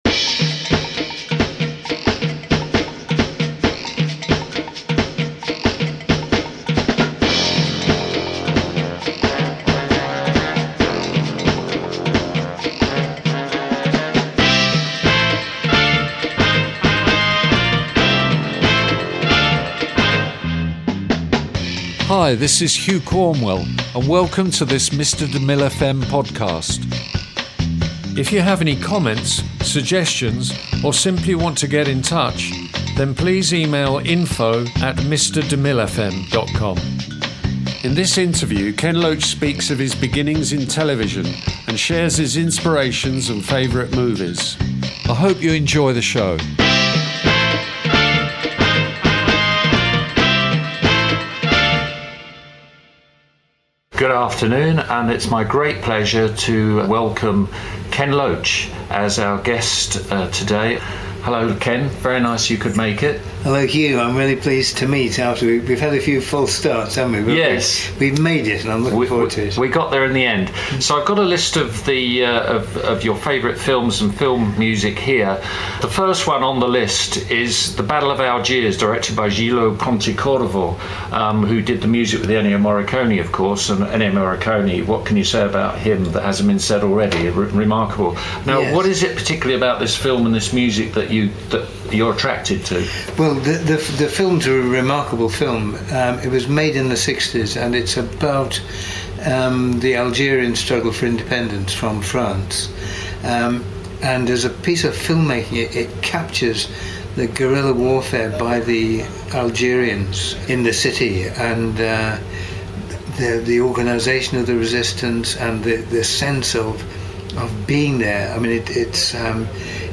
INTERVIEW KEN LOACH
Ken Loach in conversation.